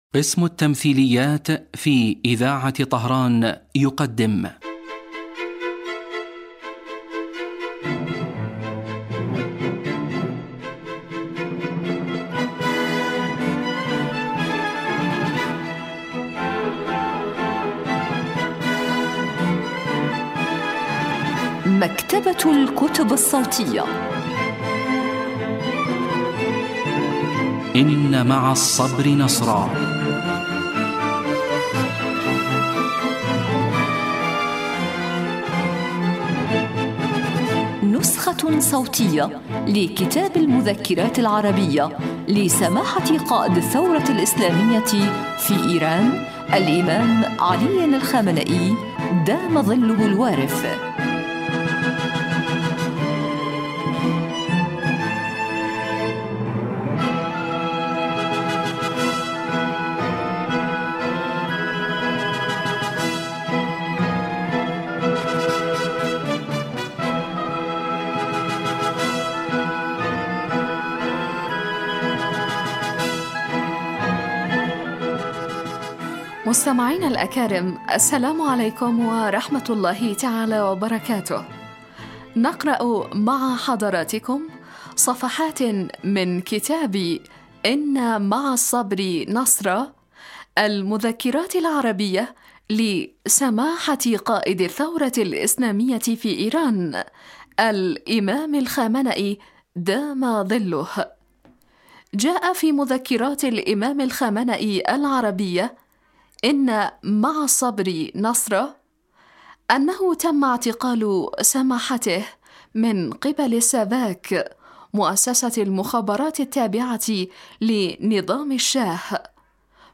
إذاعة طهران- إن مع الصبر نصرا: نسخة صوتية لكتاب المذكرات العربية لقائد الثورة الإسلامية الإمام الخامنئي (دام ظله).